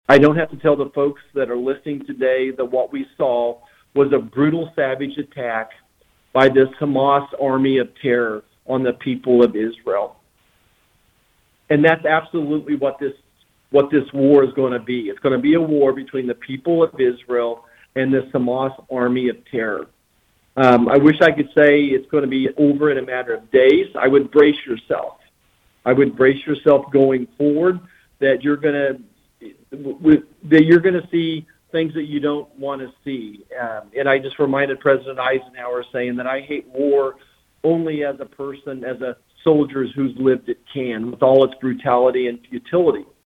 Senator Marshall discusses Israel, Medicare enrollment during telephone town hall